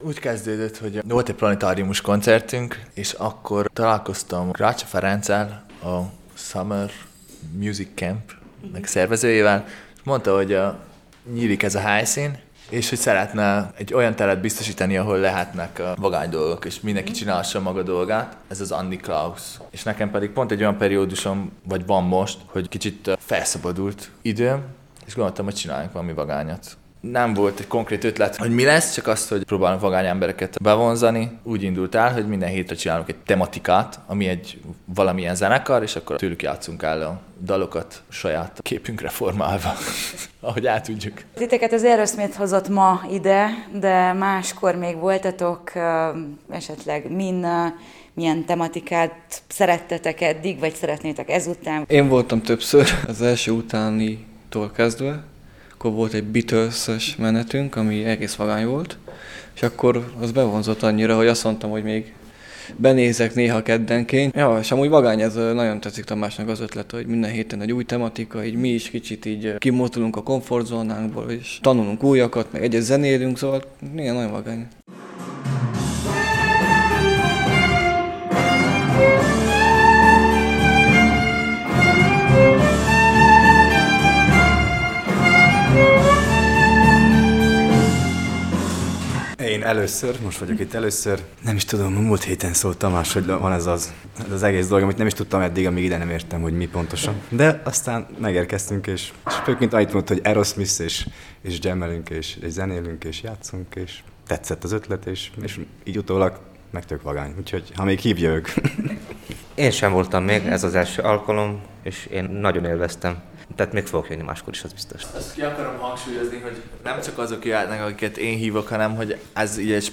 Ezen a kedden Aerosmith-dalokat játszottak gitáron, basszusgitáron és – hegedűn! Mi is ott voltunk, meghallgattuk, majd kicsit faggattuk a résztvevő zenészeket.